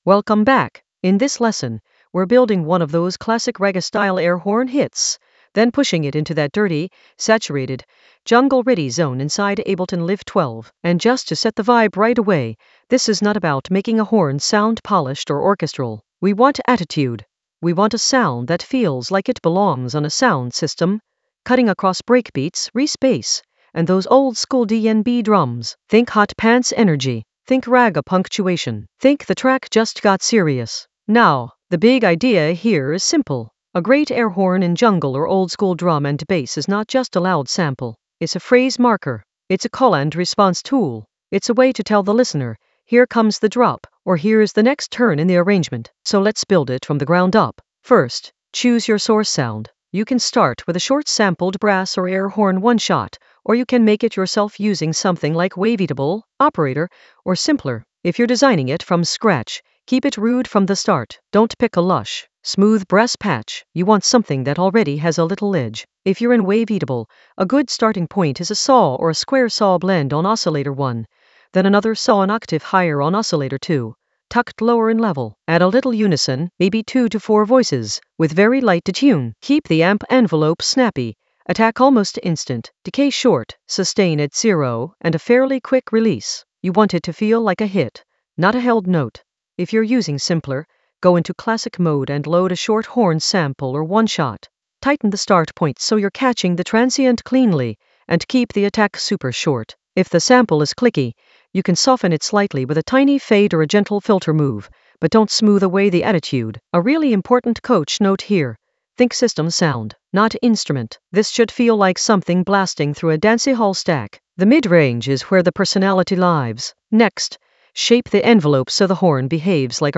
Narrated lesson audio
The voice track includes the tutorial plus extra teacher commentary.
An AI-generated intermediate Ableton lesson focused on Hot Pants masterclass: air horn hit saturate in Ableton Live 12 for jungle oldskool DnB vibes in the Ragga Elements area of drum and bass production.